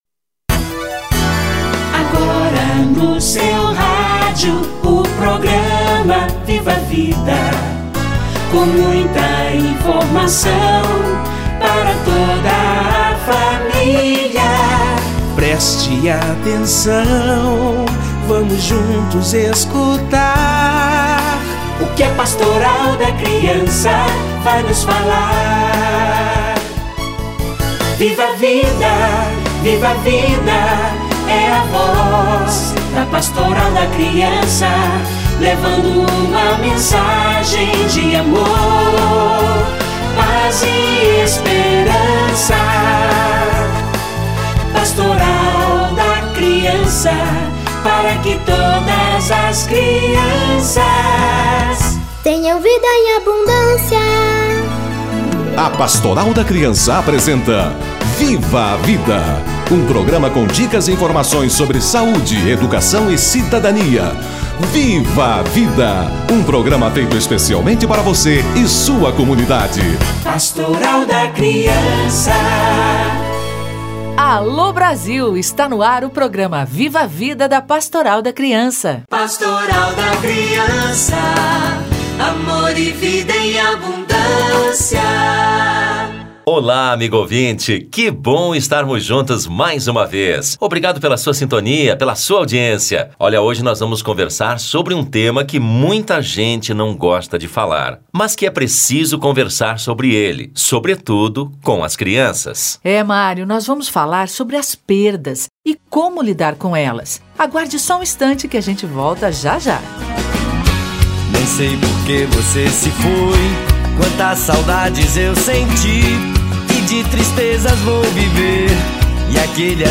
Como a criança lida com as perdas - Entrevista